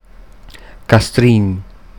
Standarditalienische Form
Castrin_Standard.mp3